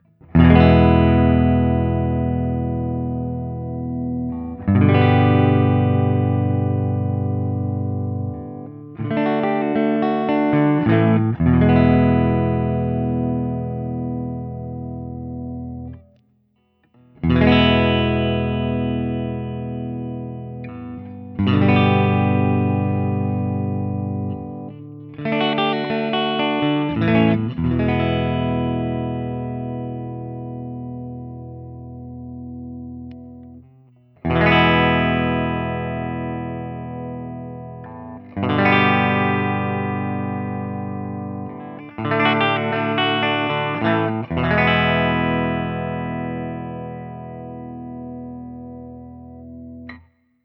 The guitar has a great jangly sound from those LB1 pickups and accentuating that is where I’d find myself the most happy with this instrument.
ODS100 Clean
Open Chords #1
This is the first time I used my new Axe-FX III for recording which I did direct to Audacity to my Mac Pro.
For each recording I cycle through the neck pickup, both pickups, and finally the bridge pickup. All knobs on the guitar are on 10 at all times.
Guild-TBird-ST-ODS100-Open1.wav